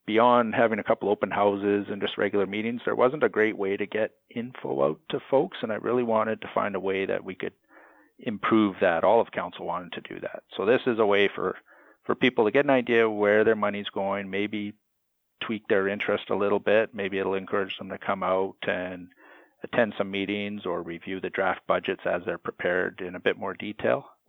Councillor Russell Brewer says the goal is to gather feedback on people’s spending priorities while also raising awareness of how the tax process works.